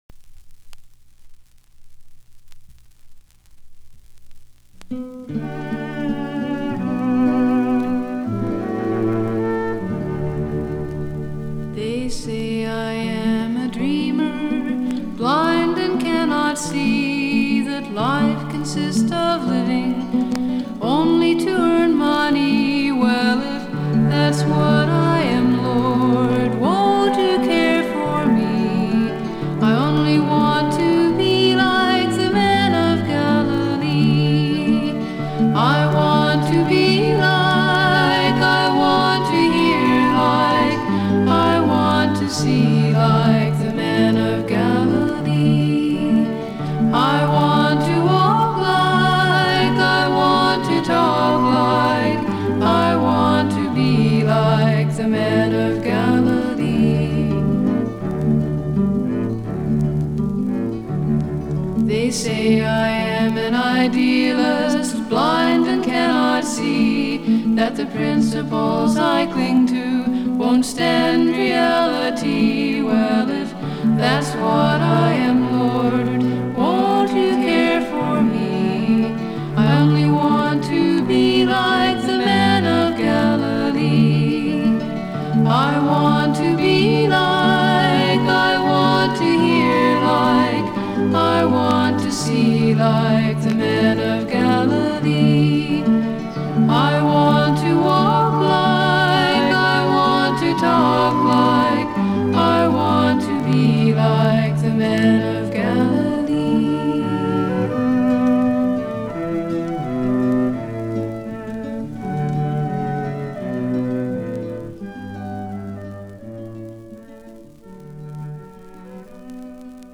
original xian folk-pop. Outstanding sparse production